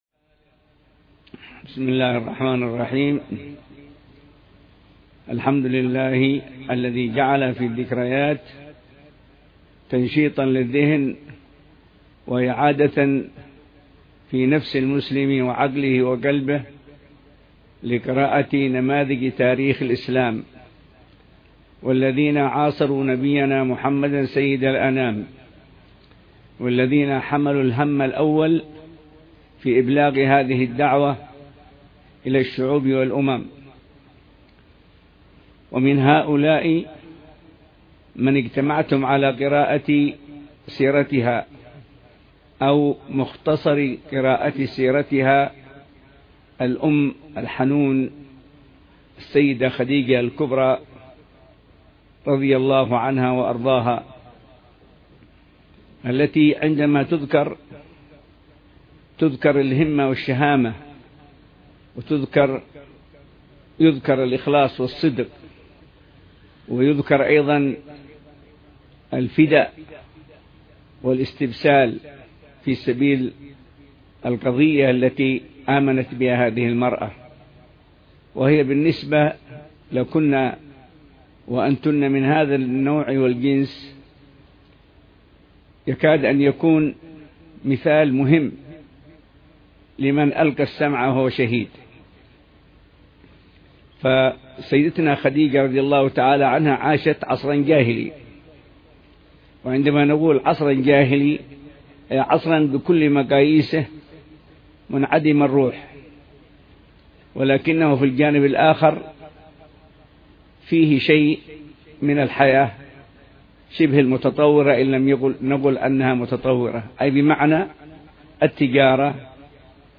عصر الإثنين 10 صفر 1439هـ بدار الصفا – الرحبة – تريم – حضرموت
محاضرة الحبيب أبوبكر المشهور للنساء بعنوان: خديجة الكبرى.. مدرسة عز وشرف وديانة مع كل ذكرى